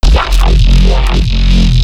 LM_Growl Bass 17.wav